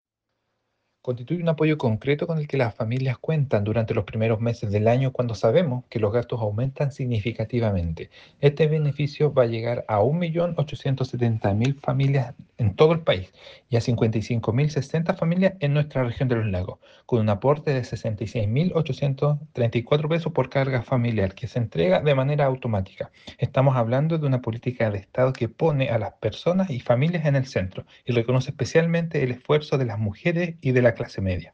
El Seremi del Trabajo y Previsión Social, Ricardo Ebner. informó que este beneficio llegará a 55.060 familias en la región.